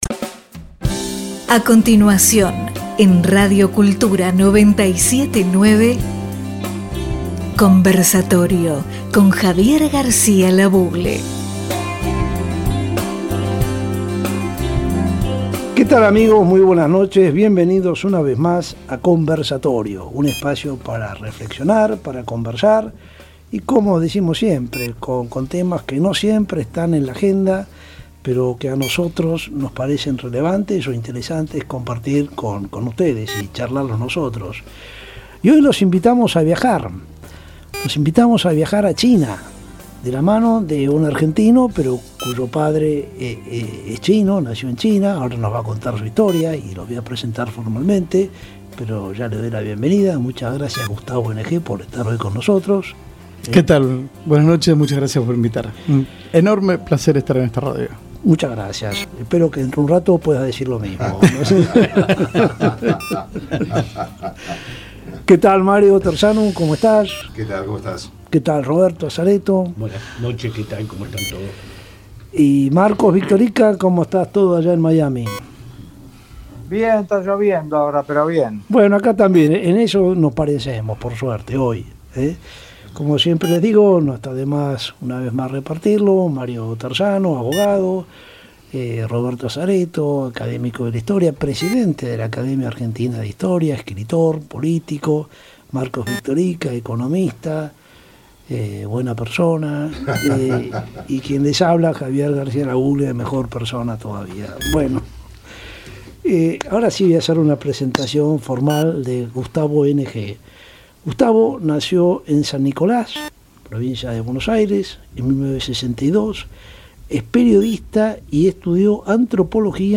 Con la mesa de Conversatorio completa, salió una muy fluida e ilustrativa conversación.